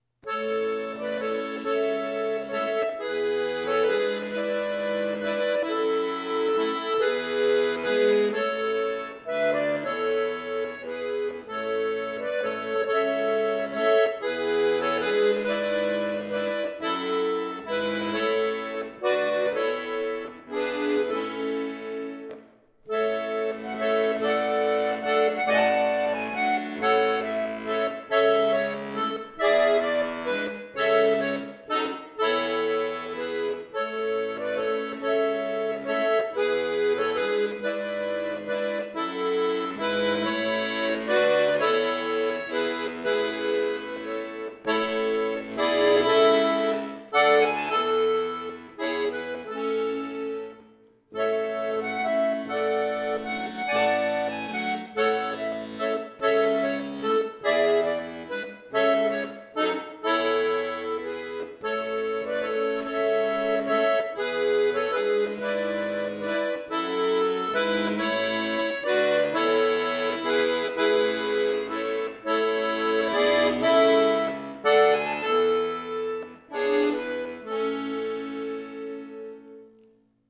neu arrangiert für Akkordeon solo